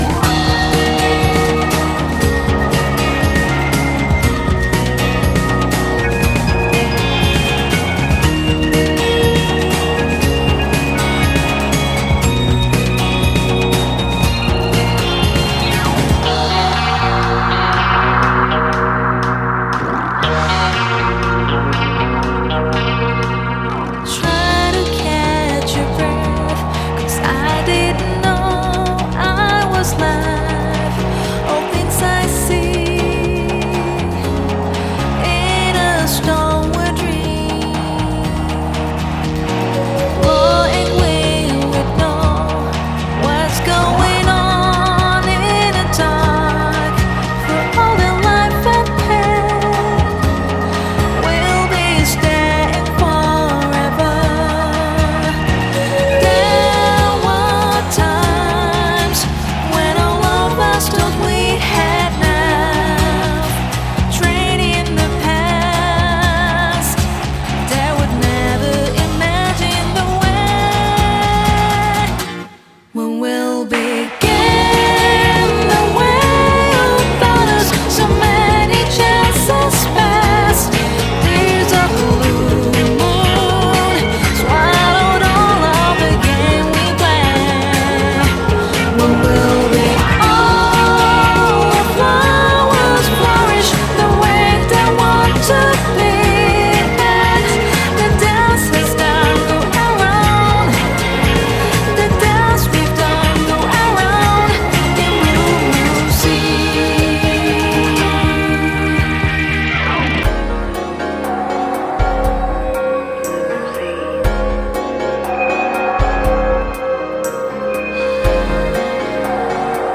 BPM120
Audio QualityMusic Cut